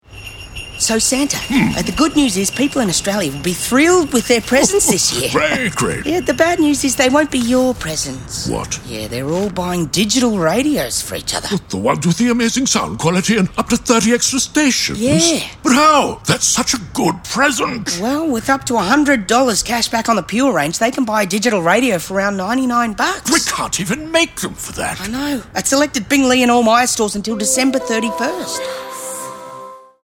The ads feature a mad Santa who wants to steal the presents and claim them as his own
The three 30-second ads feature Santa Claus speaking to an elf about the digital radio stations, which a number of Australians will receive this Christmas.